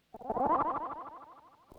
Fly By.wav